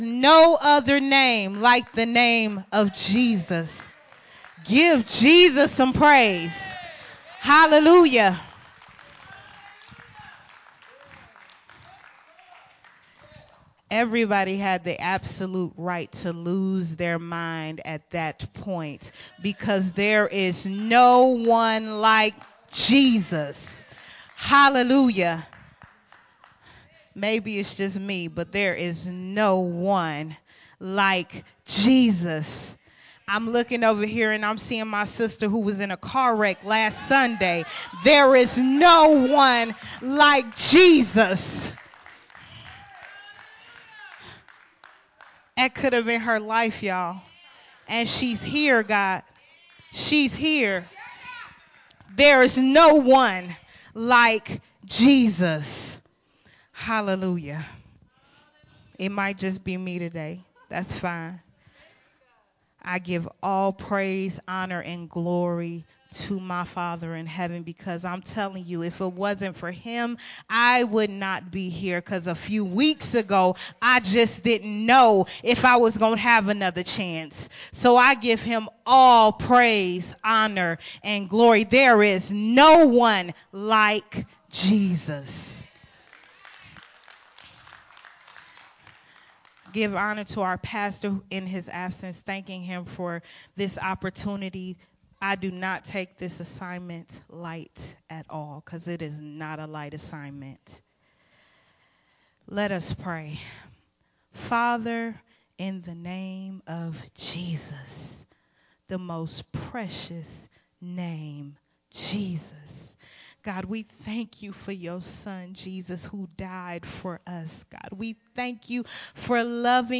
Part 5 of the sermon series
recorded at the Unity Worship Center